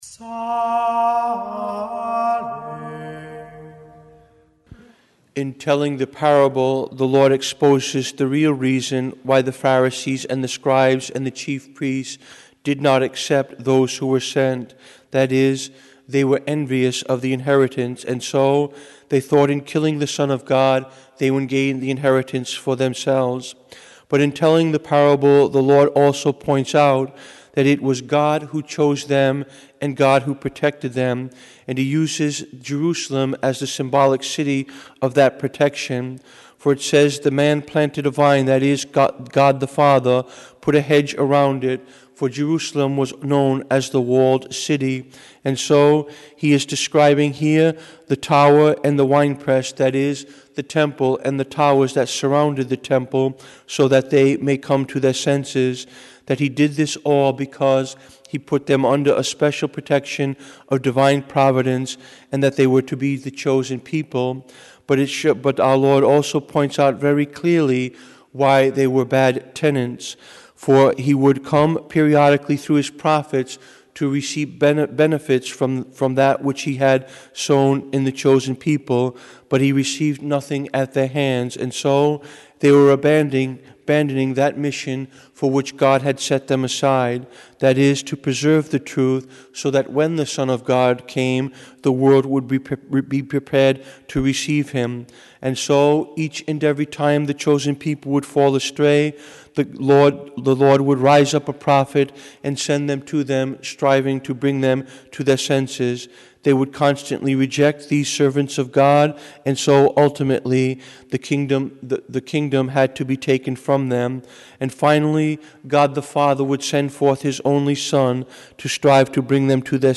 Homily
Mass: Monday 9th Week of Ordinary Time - Wkdy - Form: OF Readings: 1st: 2pe